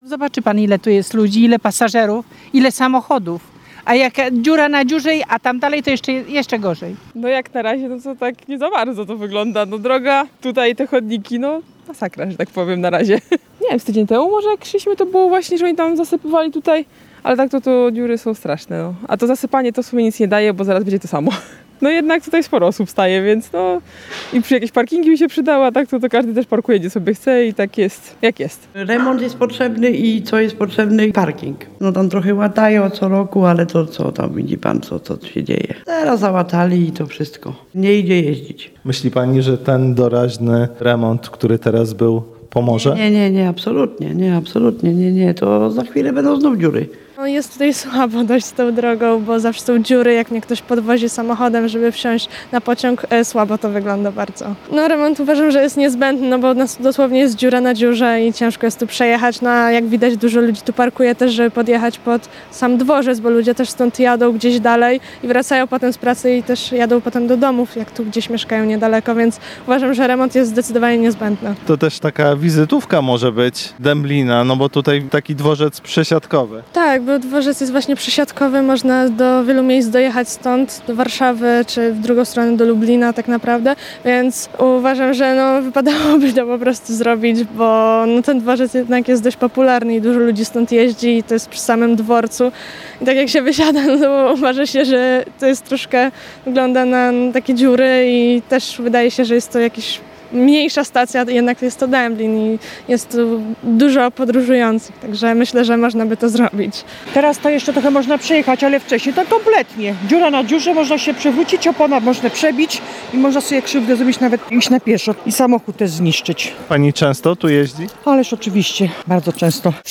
– Ulica jest doraźnie „łatana”, ale to niewiele daje – mówią w rozmowie z naszym reporterem okoliczni mieszkańcy oraz podróżni